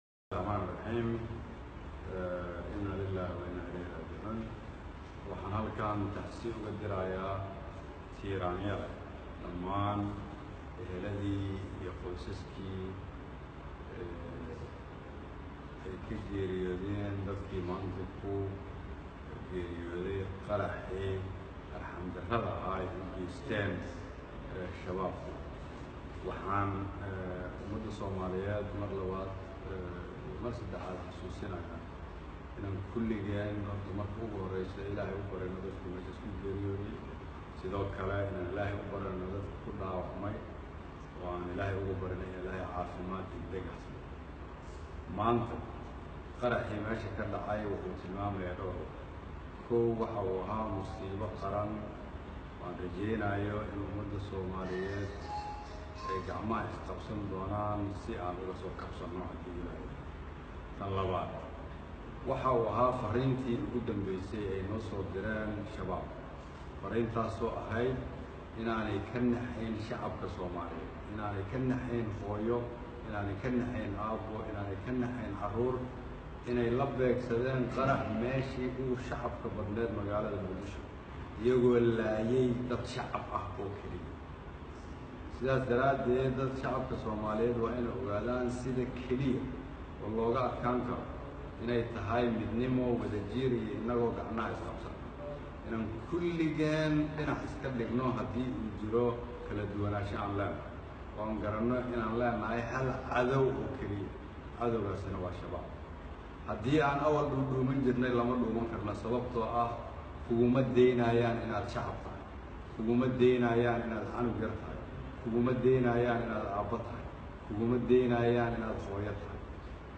15 oktoober 2017 (Puntlandes) Ra’iisul Wasaaraha DFS Xasan Cali Kheyre ayaa xalay ka hadlay qaraxii shalay galab ka dhacay Isgoyska Zoobe, kaasoo dad fara badan ay ku dhamaadeen.
Rwasaare-Khayre-oo-hadlay-qaraxii-Muqdisho.mp3